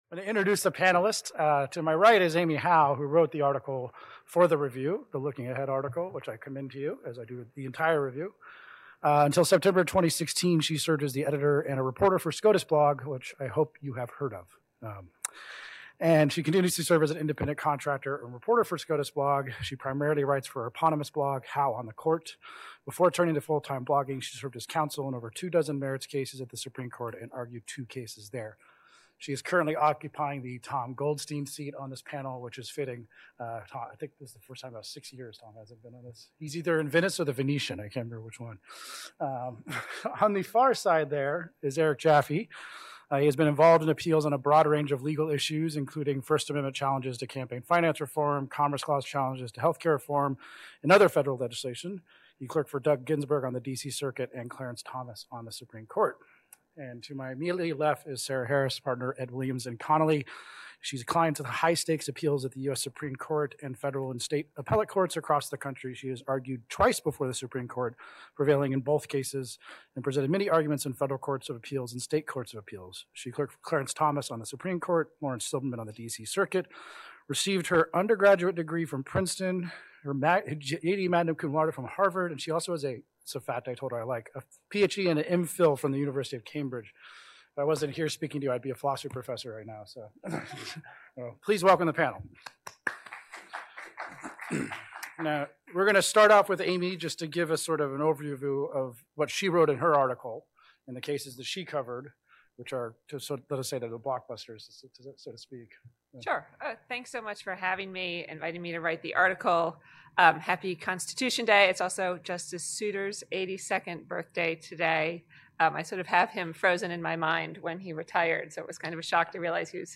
20th Annual Constitution Day: Panel 4: Looking Ahead: October Term 2021
Cato’s annual Constitution Day symposium marks the day in 1787 that the Constitutional Convention finished drafting the U.S. Constitution. We celebrate that event each year with the release of the new issue of the Cato Supreme Court Review and with a day‐long symposium featuring noted scholars discussing the recently concluded Supreme Court term and the important cases coming up.